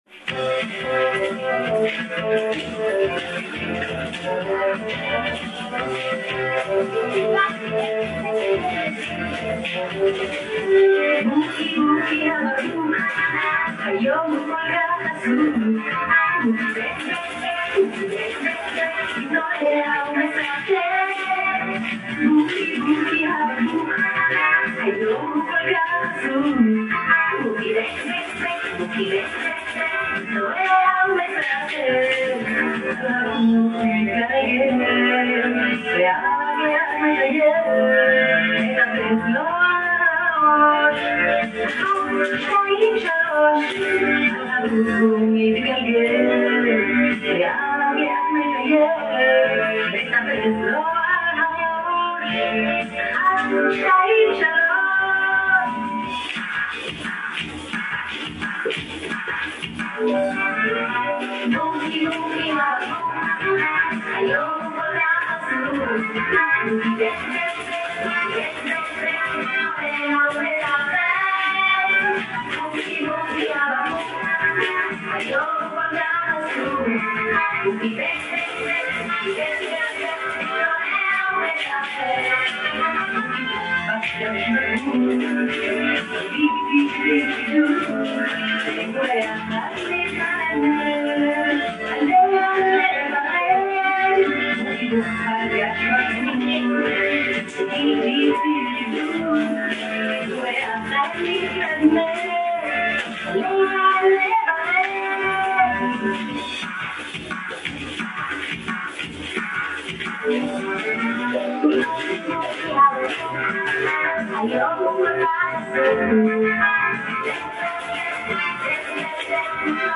Я попытался выделить голос на фоне музыки-может так будет легче разобрать...(на мой взгляд это финно-угорская группа языков)